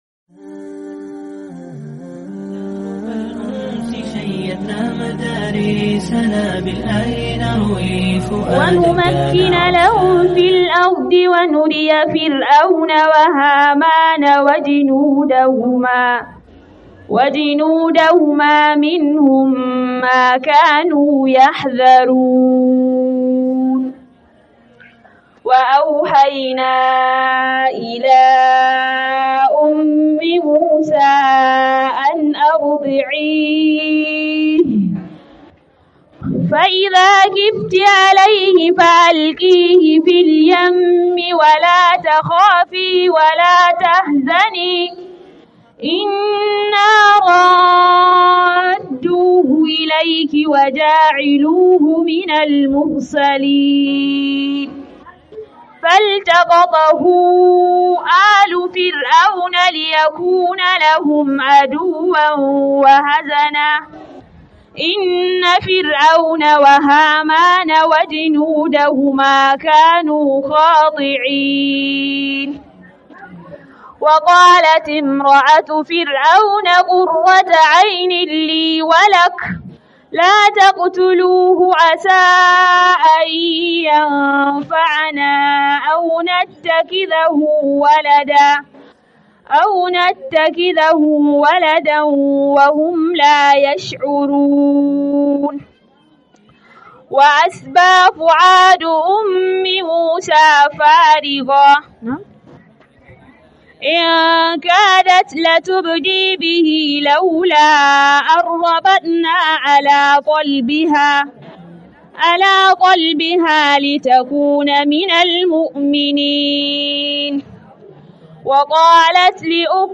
Wa'azin Walima
Muhadara